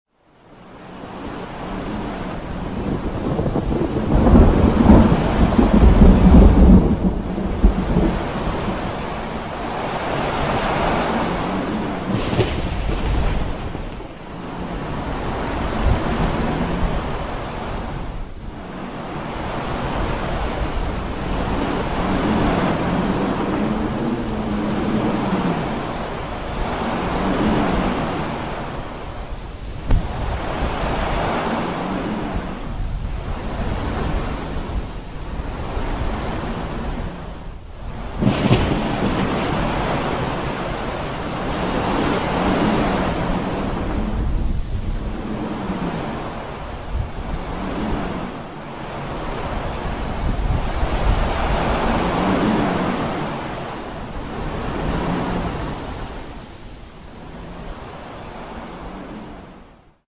Approaching Storm
Category: Animals/Nature   Right: Personal